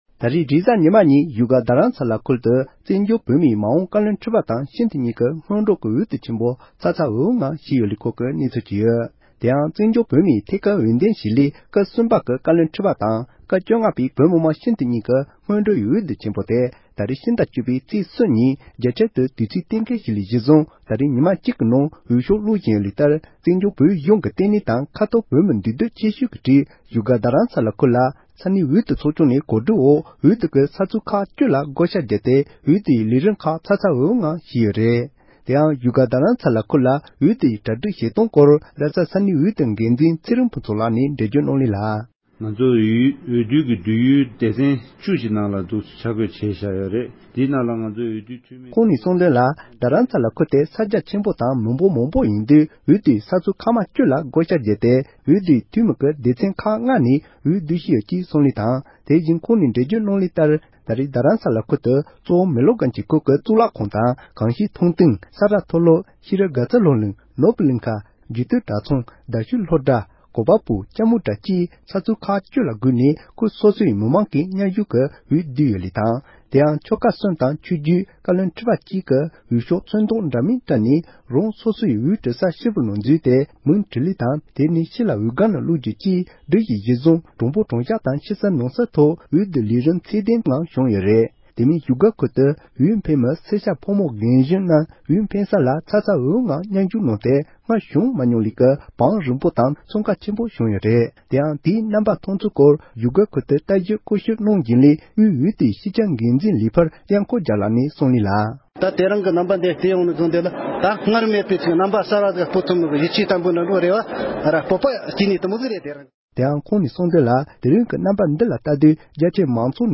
བཞུགས་སྒར་དུ་བཀའ་བློན་ཁྲི་པ་དང་སྤྱི་འཐུས་ཀྱི་སྔོན་འགྲོའི་འོས་བསྡུ་ནང་ཞུགས་མཁན་མི་སྣ་ཁག་ཅིག་ལ་བསམ་ཚུལ་བཀའ་དྲིན་ཞུས་པ།
སྒྲ་ལྡན་གསར་འགྱུར།